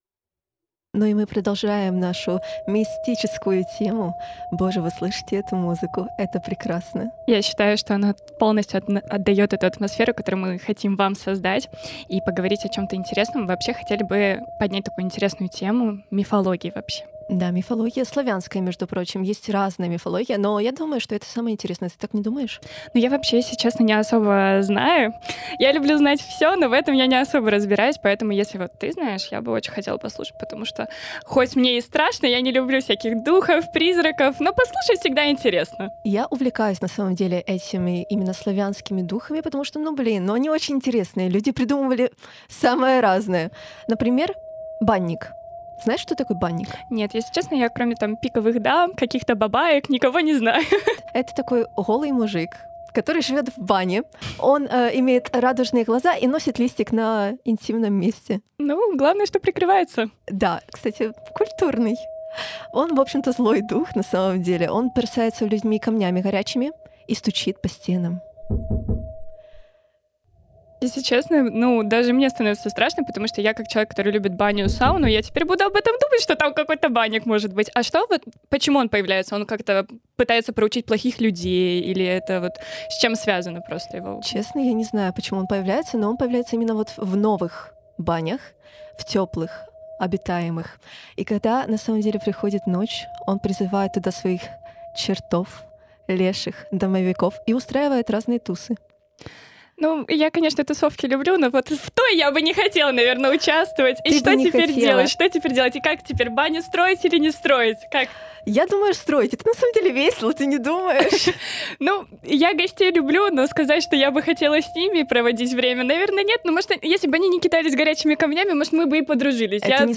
Bardziej szczegółowo o tym rozmawialiśmy w audycji Szto tam?: